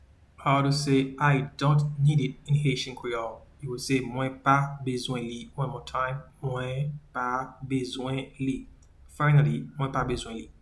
Pronunciation:
I-dont-need-it-in-Haitian-Creole-Mwen-pa-bezwen-li.mp3